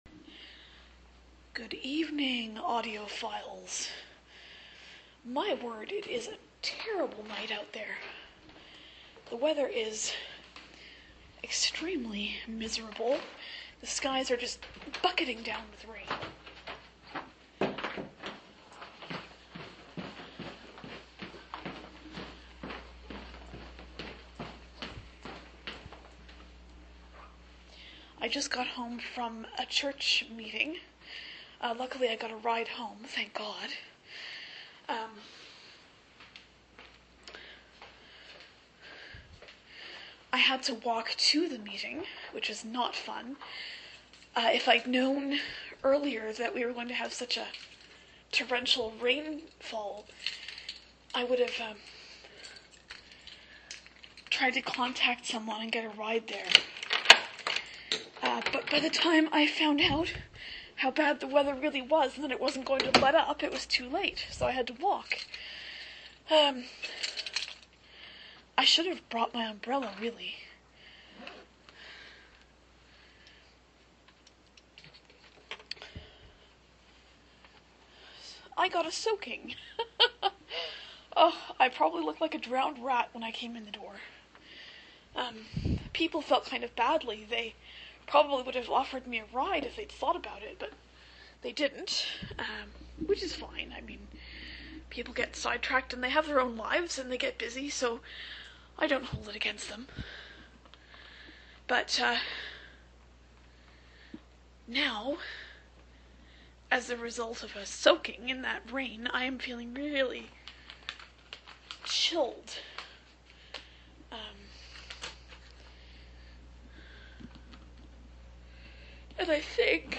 I guess I just wanted to ramble whilst making my hot chocolate.